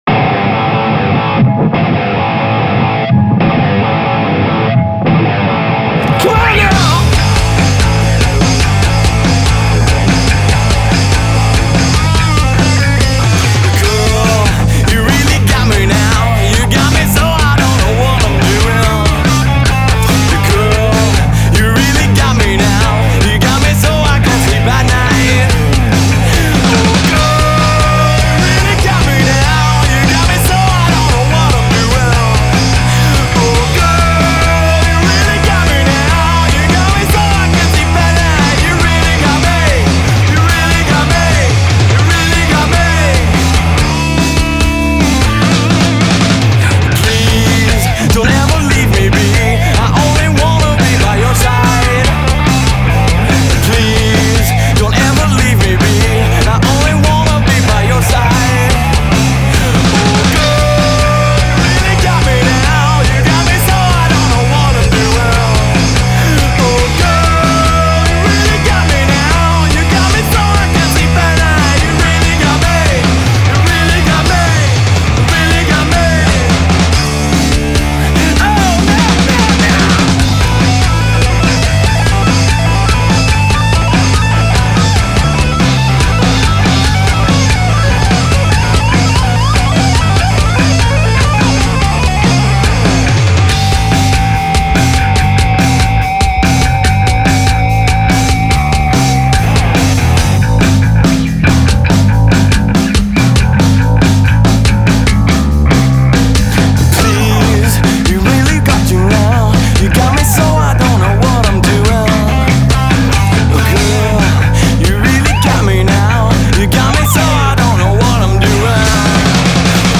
(live in the studio recording)